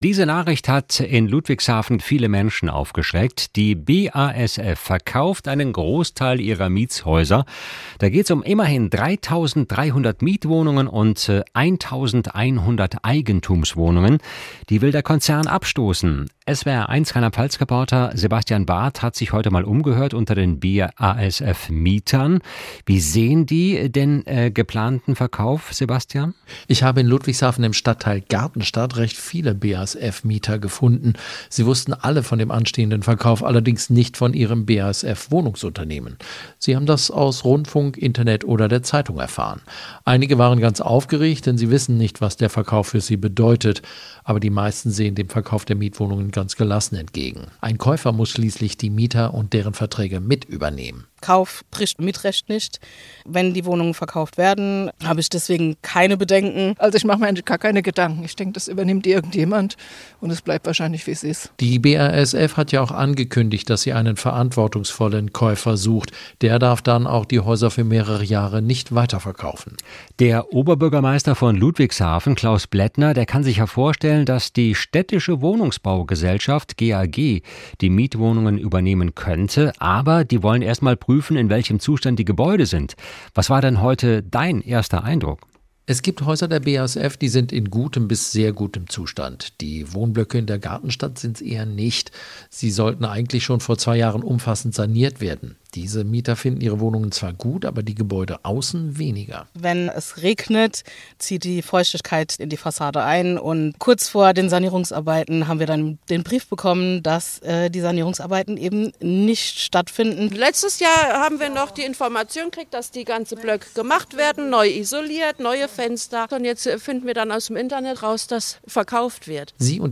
Die BASF will 4.400 Wohnungen verkaufen und hat für die Mieter eine Sozialcharta angekündigt. Wir haben Mieter aus Ludwigshafen zu den Plänen befragt.